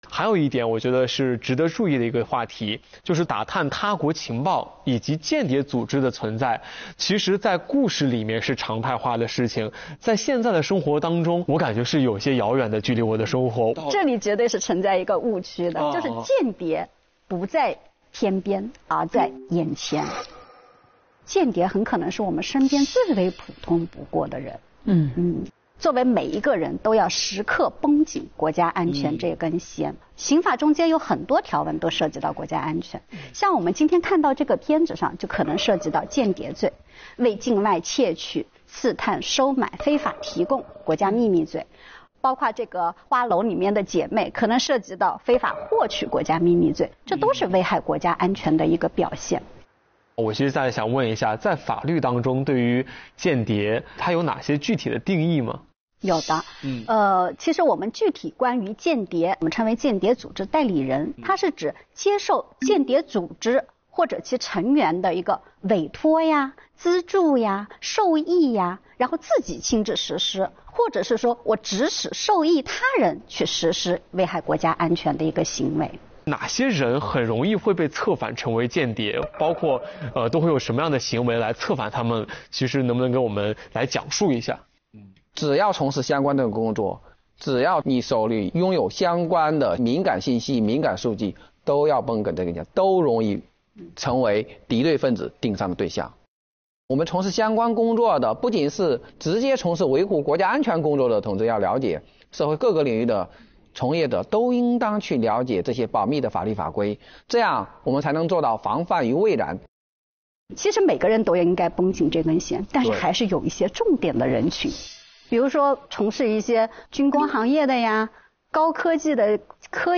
最高人民法院郭慧法官和段凰法官